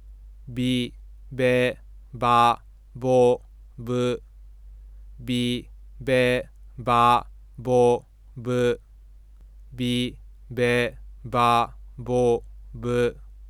bibebabobu.wav